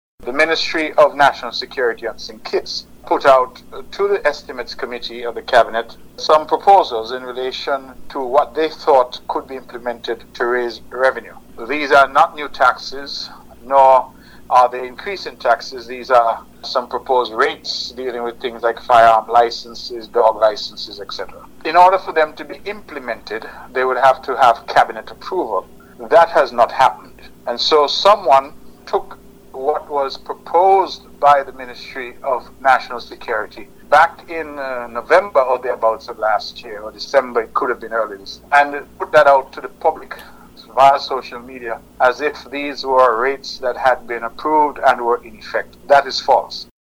The VONNEWSLINE contacted Premier of Nevis, the Hon. Mark Brantley who clarified if these estimates in the document are true and if and when they will be implemented: